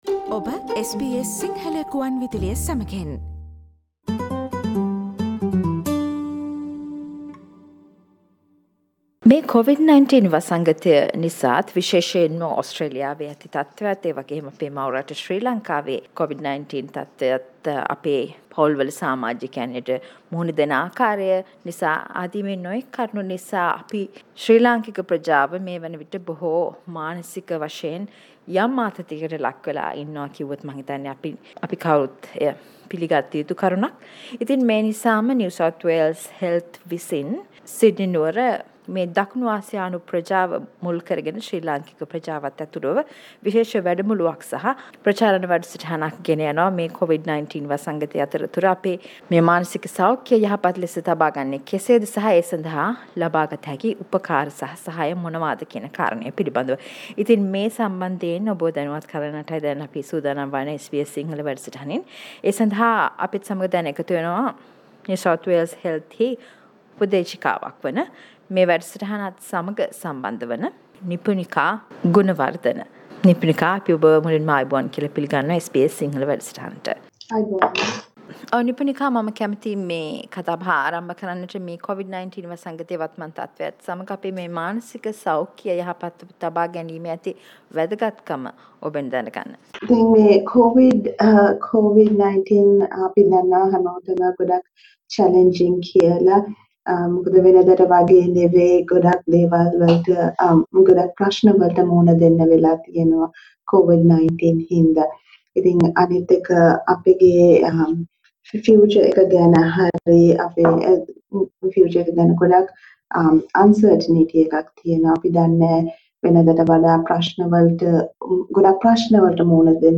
A discussion with mental health support Offcial NSW Health